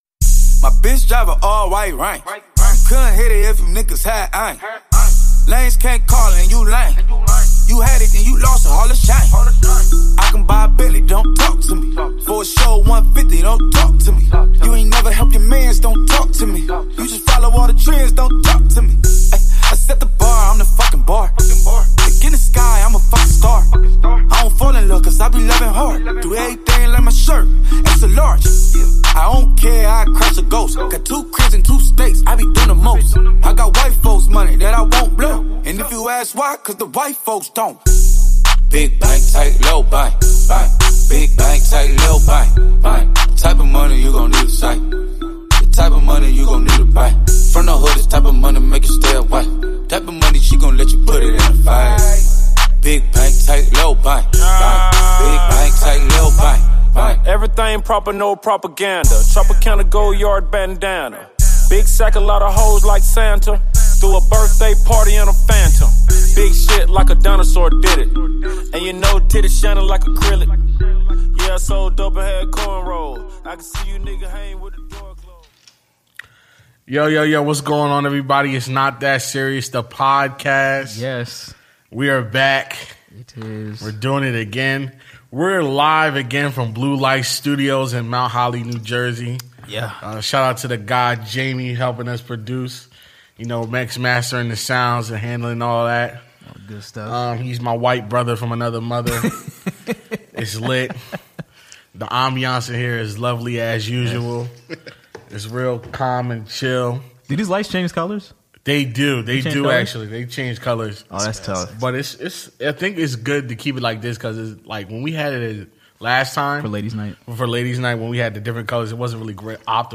(Shoutout to Blue Light Digital Sound studios for having us!)